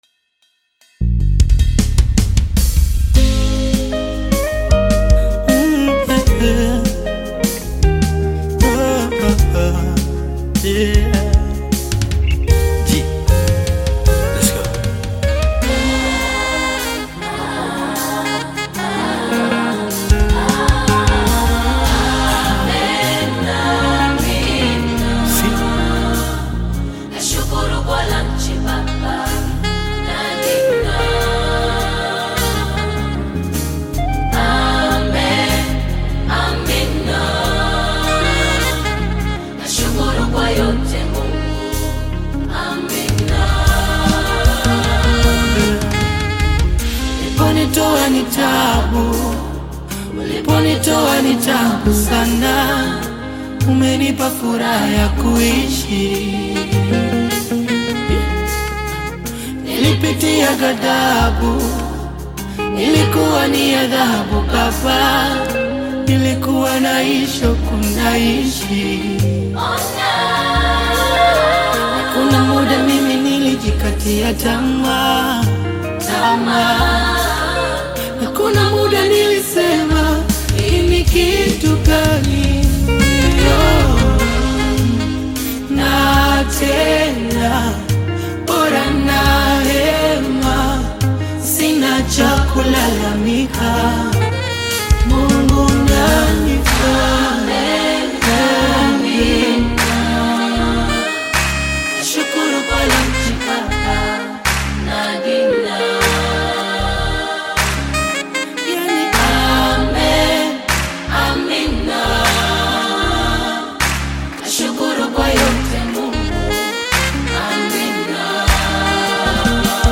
Bongo Flava music track
Bongo Flava You may also like